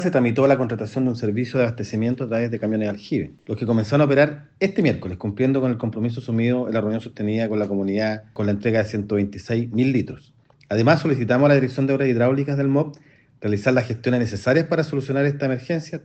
Por su parte, el delegado presidencial, Jorge Alvial, confirmó la disposición de camiones aljibe, mientras que la Dirección de Obras Hidráulicas tendrá la misión de alcanzar la solución definitiva.